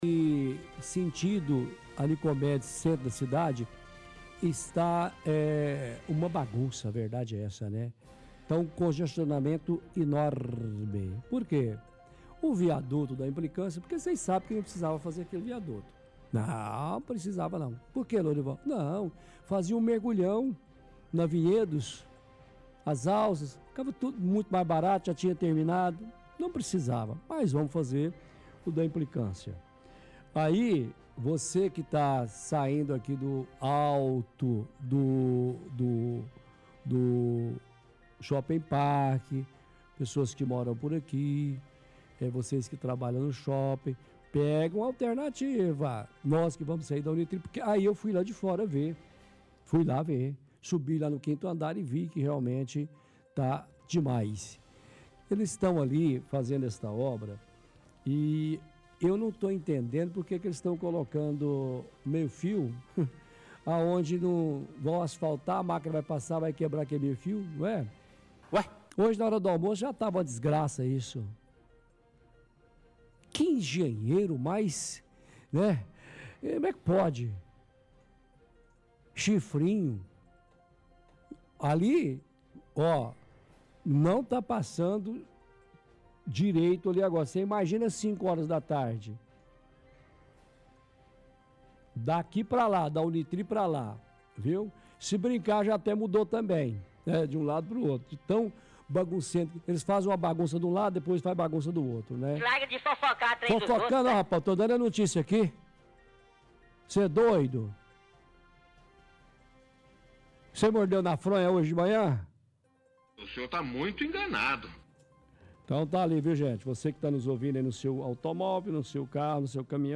-Faz ironia imitando a voz do Droninho da Prefeitura. (Veicula parte do áudio de propaganda da Prefeitura)